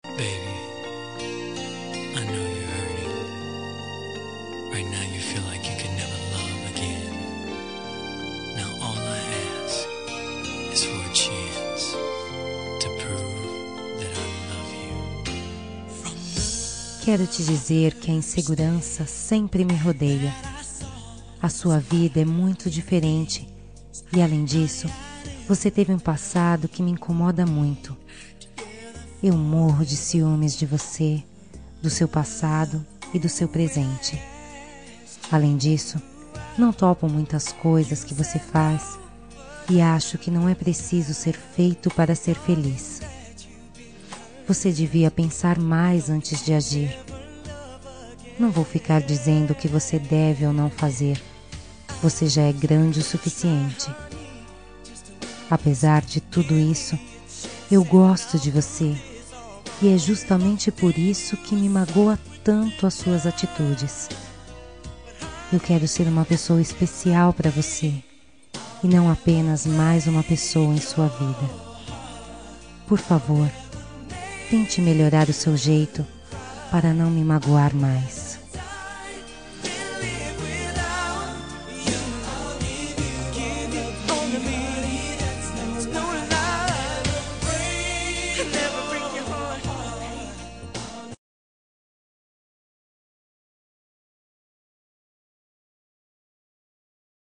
Toque para Não Terminar – Voz Feminina – Cód: 460 – Você me Magoou
460-voce-me-magoou-fem.m4a